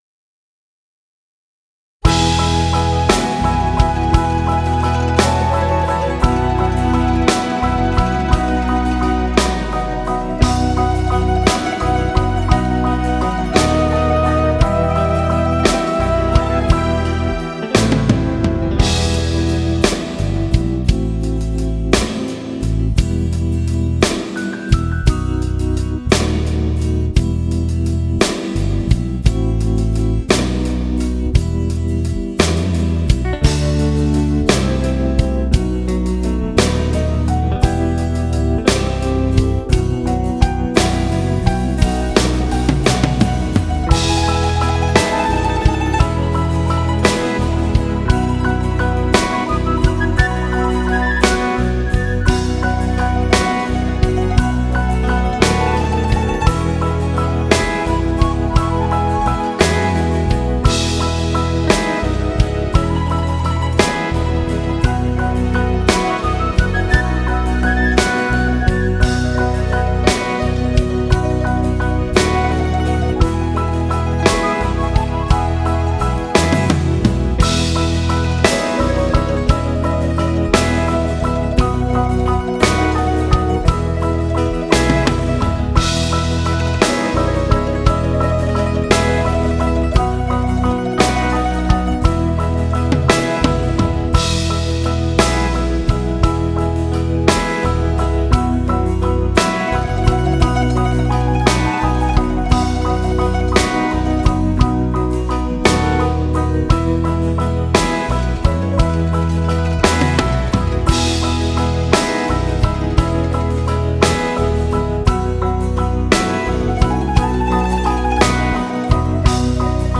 Genere: Terzinato